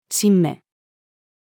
新芽-female.mp3